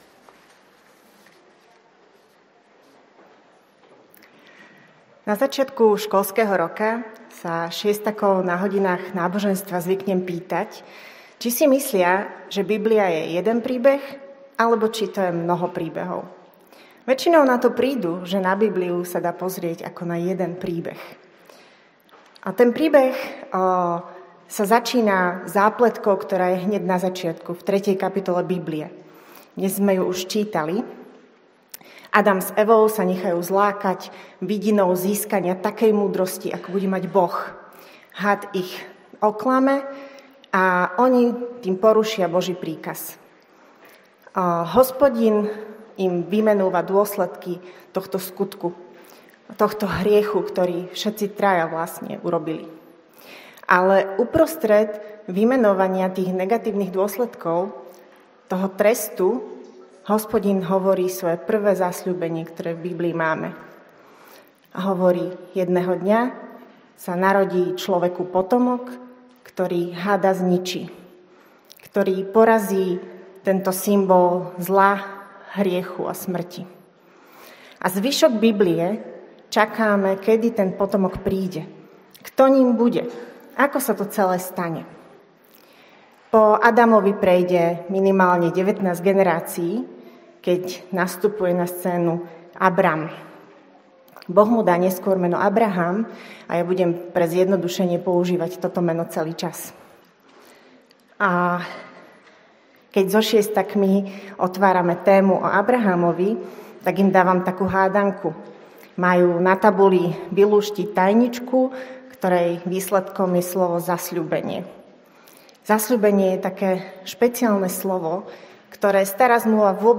Cirkev bratská | Bratislava, Cukrová - Bohoslužby
Zj 1:4-9 Podrobnosti Kázeň Prehliadač nepodporuje prehrávač.